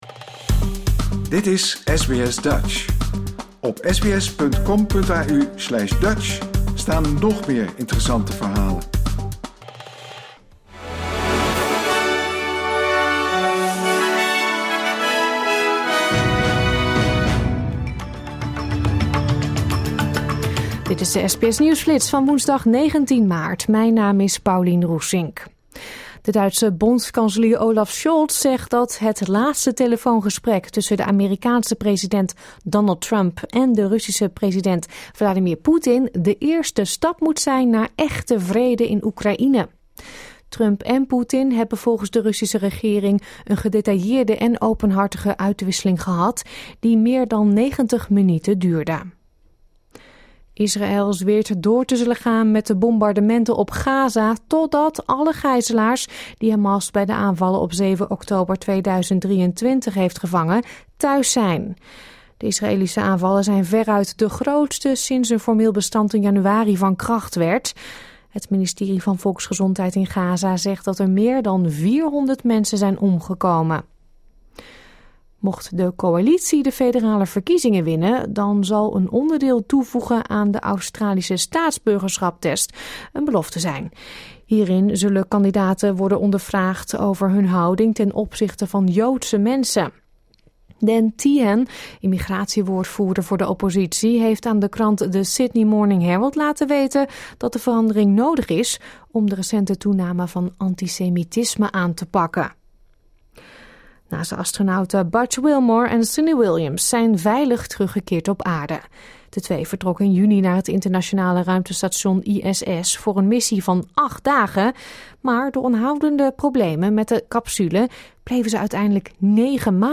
Dit is de SBS Nieuwsflits van woensdagochtend 19 maart 2025.